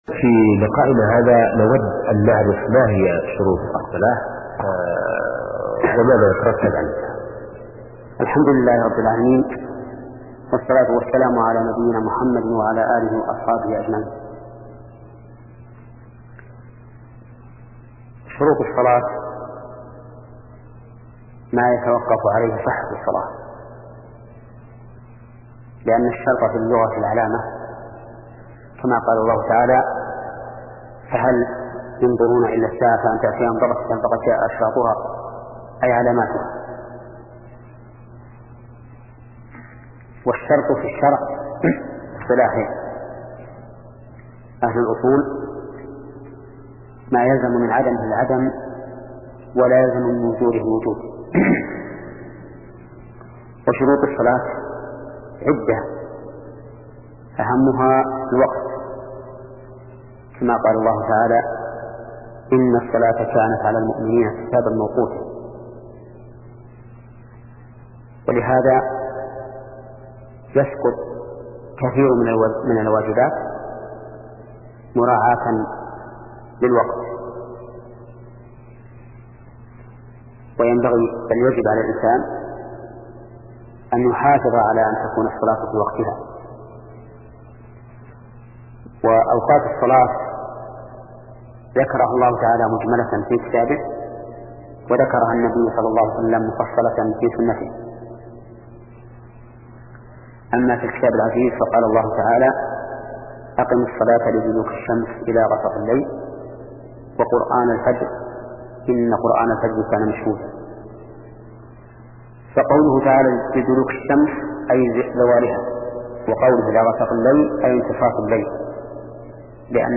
شبكة المعرفة الإسلامية | الدروس | فقه العبادات (24) |محمد بن صالح العثيمين